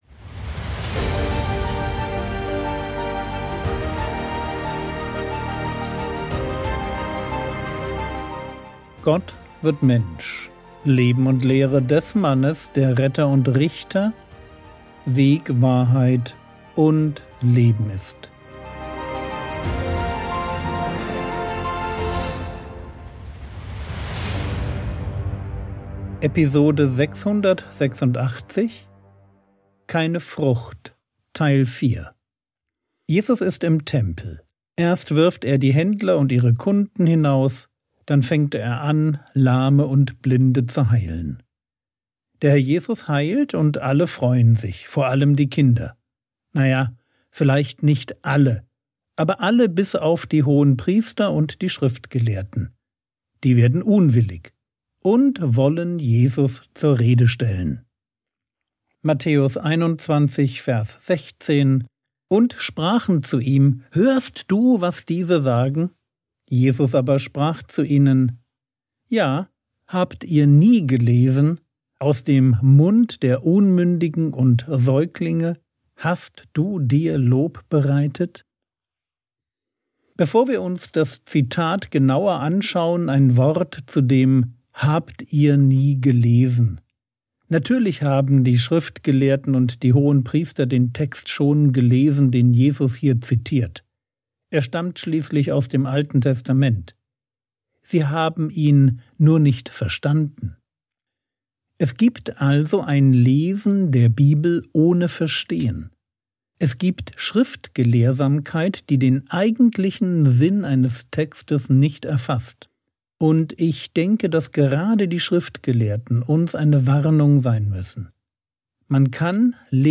Episode 686 | Jesu Leben und Lehre ~ Frogwords Mini-Predigt Podcast